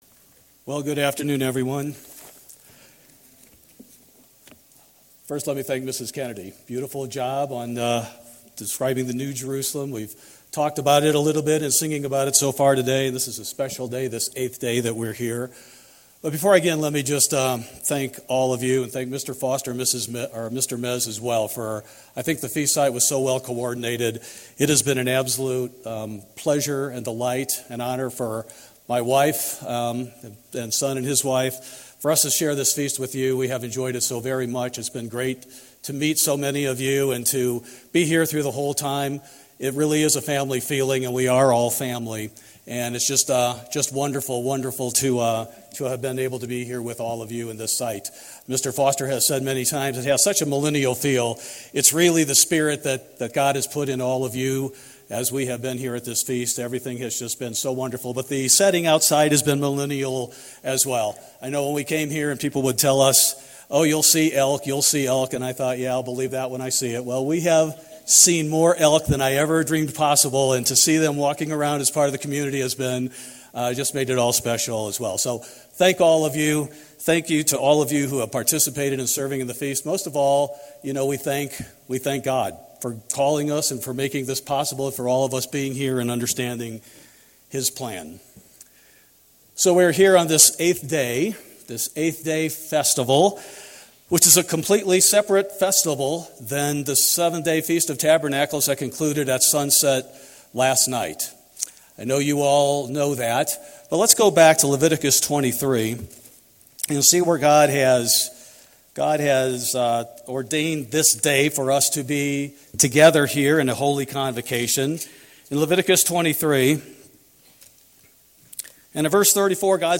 This sermon was given at the Estes Park, Colorado 2023 Feast site.